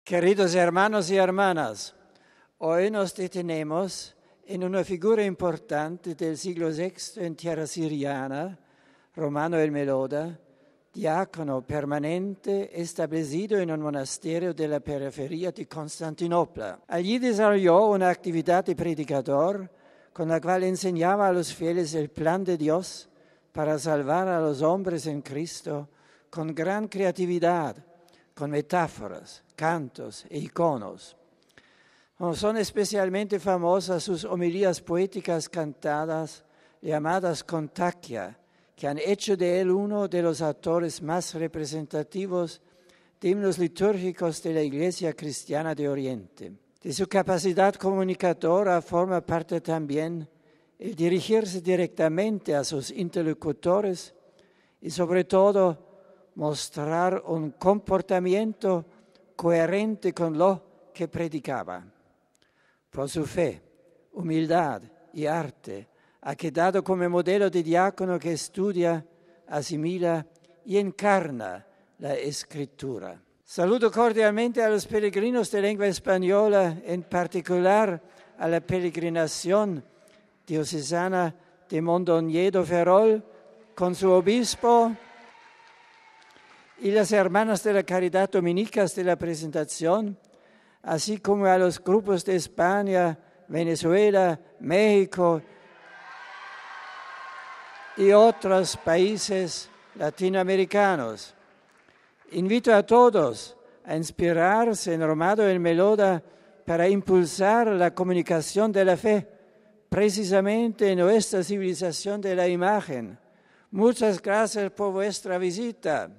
Este ha sido el resumen que ha hecho el Papa en español de su catequesis.